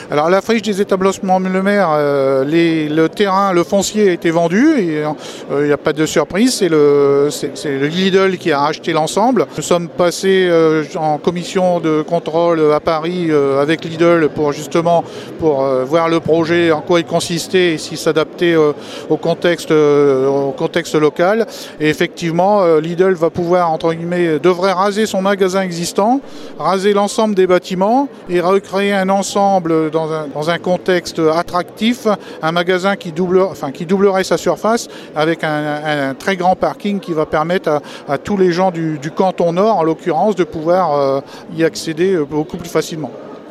Patrick TILLIER Maire de la commune l’a confirmé à l’occasion de ses vœux Mercredi soir .